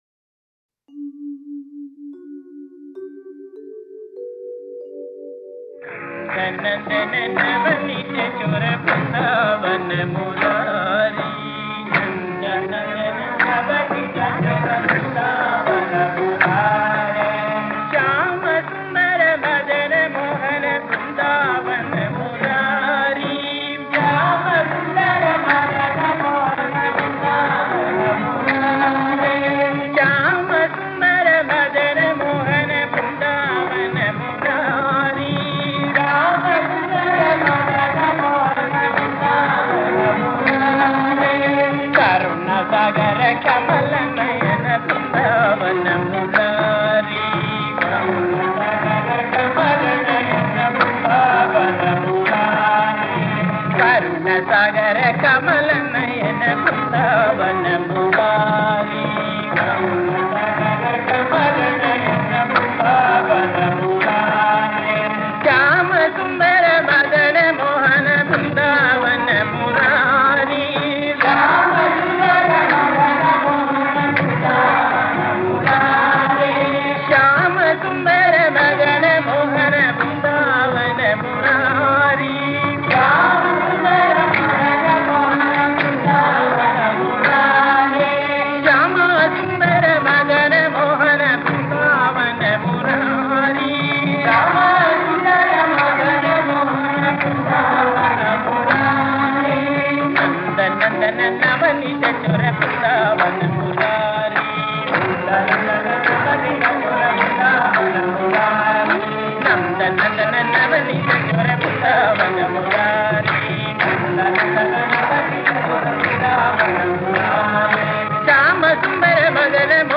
1. Devotional Songs
Mishra Khamas 6 Beat  Men - 1 Pancham  Women - 5 Pancham
Mishra Khamas
6 Beat / Dadra
1 Pancham / C
5 Pancham / G
Lowest Note: G2 / E
Highest Note: M1 / F (higher octave)